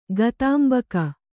♪ gatāmbaka